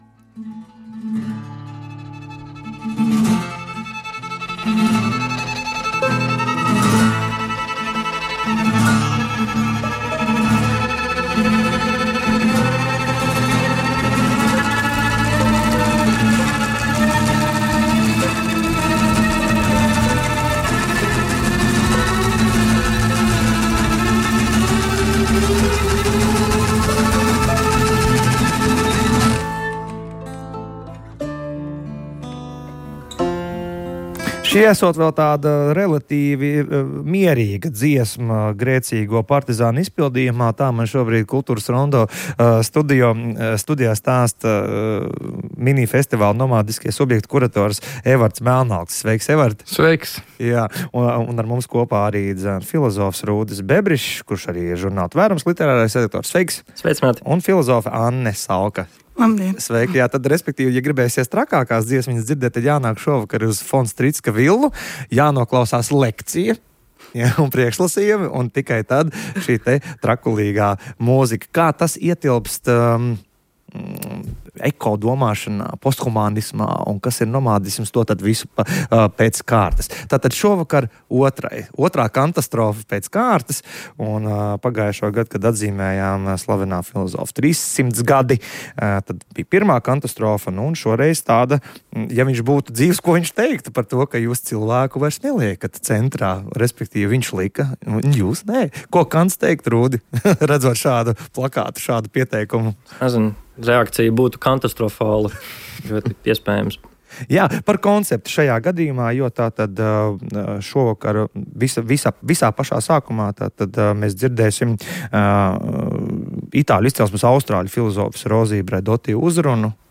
Domāt par cilvēka un citbūtņu stāvokli klimata katastrofas noteiktā realitātē un to, kā tajā veidot attiecības ar pasauli un citām to apdzīvojošajām būtnēm, balstoties uz posthumānisma idejām- aicina kritikas minifestivāls Nomadiskie subjekti. Studijā filozofi